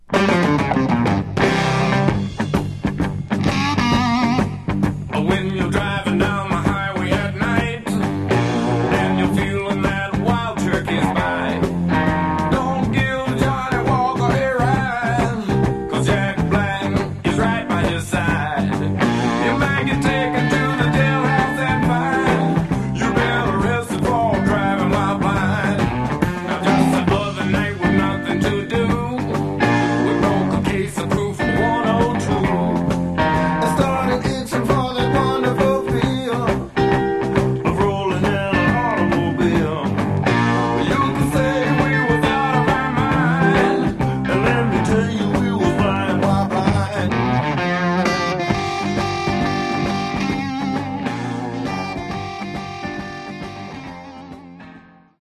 Genre: Southern Rock